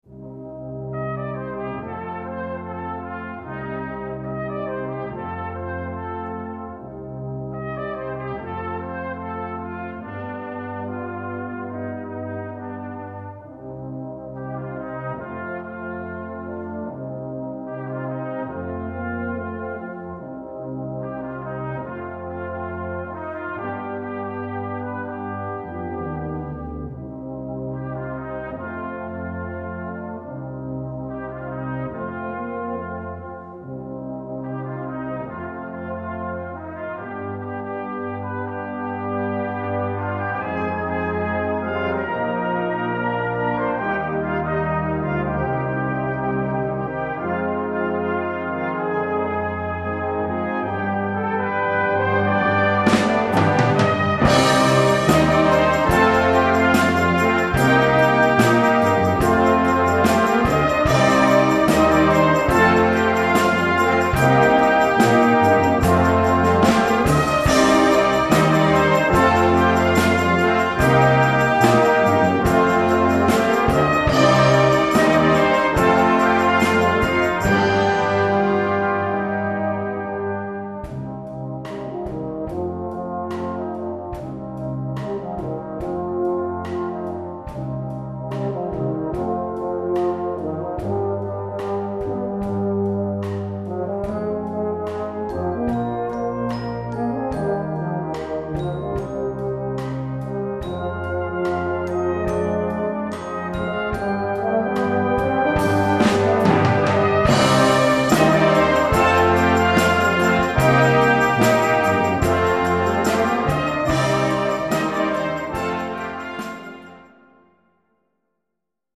Brass Band